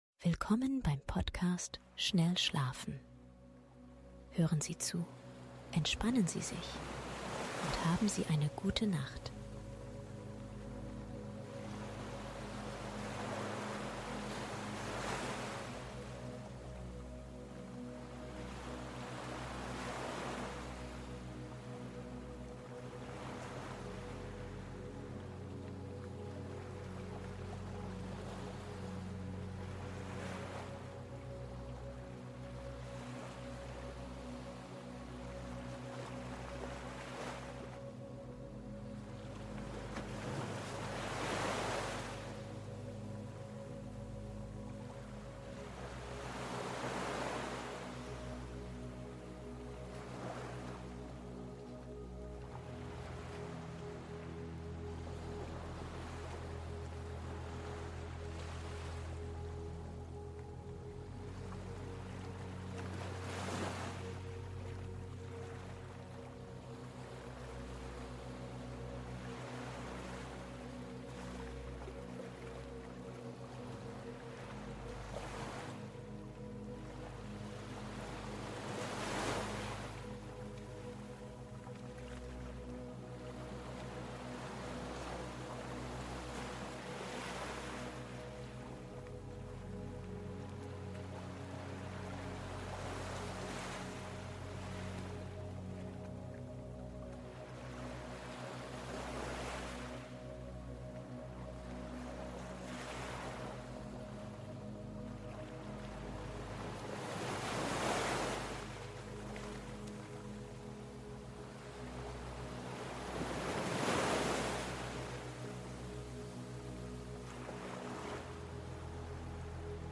Beruhigende OZEANWELLEN und Entspannende MELODIEN für Erholsamen SCHLAF